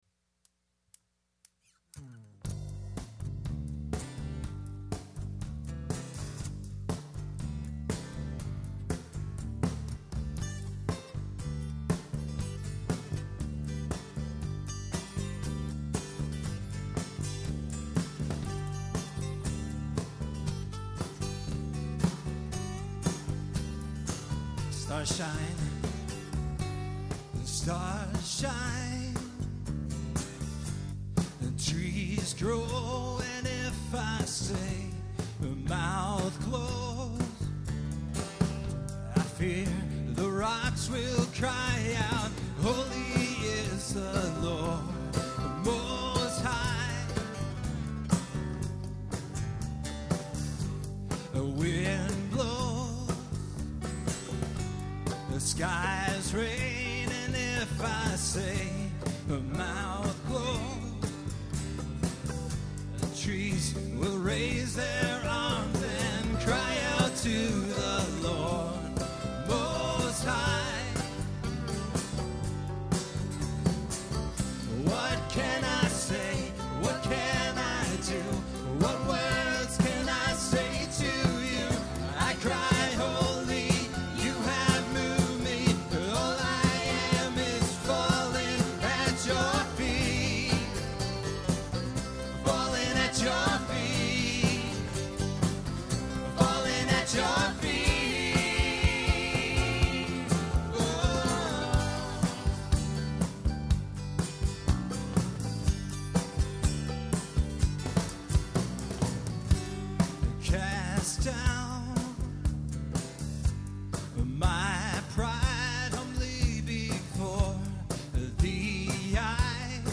Performed live at Terra Nova - Troy on 1/6/08.